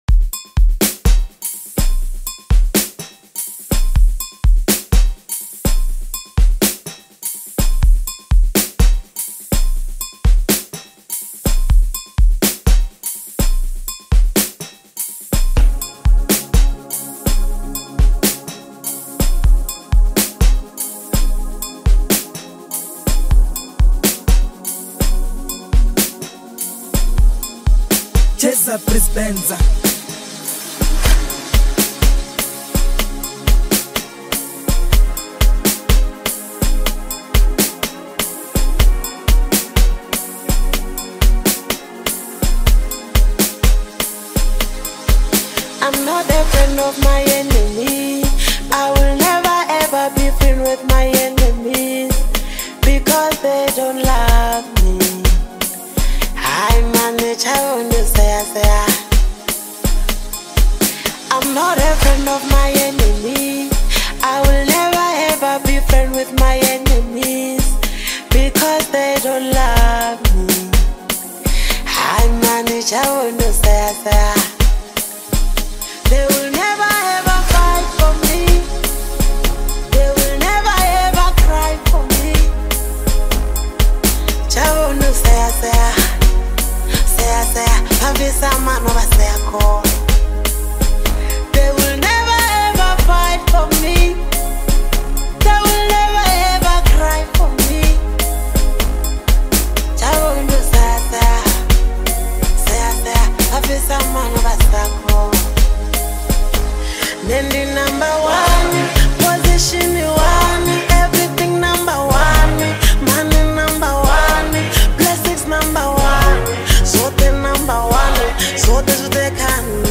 ethereal saxophone melodies
smooth log drums
harmonious balance of soulful depth and Amapiano bounce